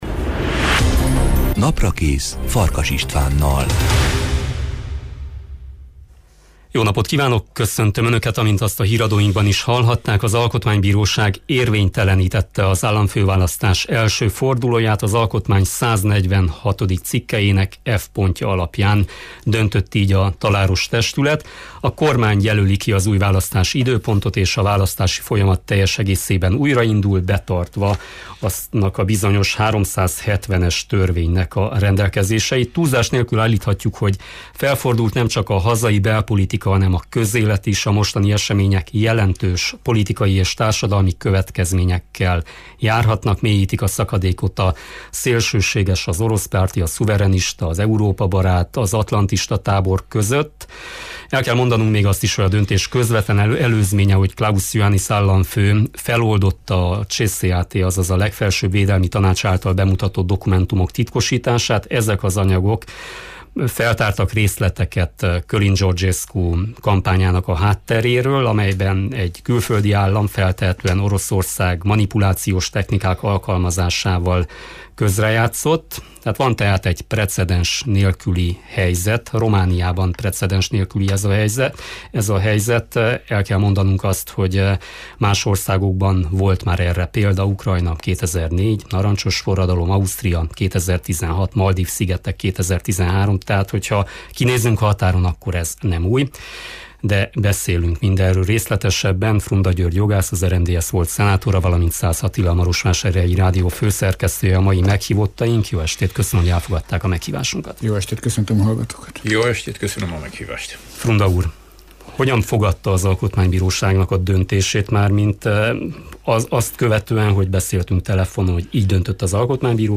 Erről a precedens nélküli rendkívüli helyzetről beszélgetünk a mai Naprakészben.